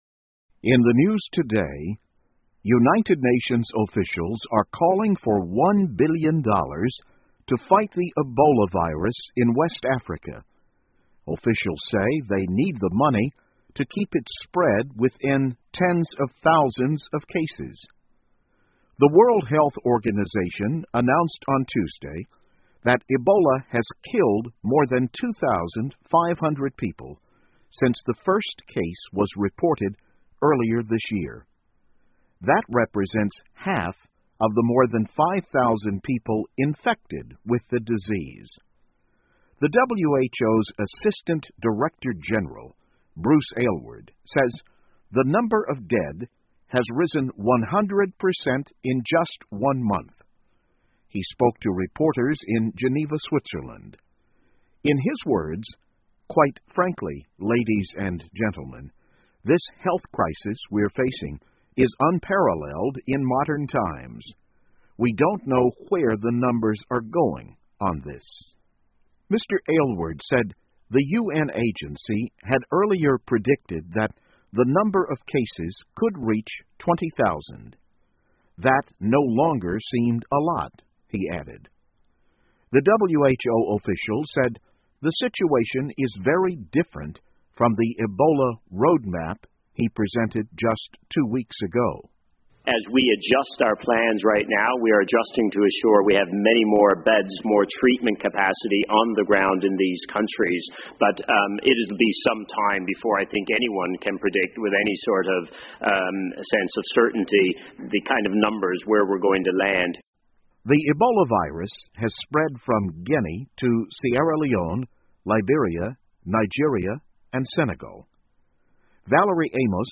VOA慢速英语2014 美国、联合国加入抗击埃博拉病毒 听力文件下载—在线英语听力室